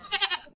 sounds_goat_bleat_01.ogg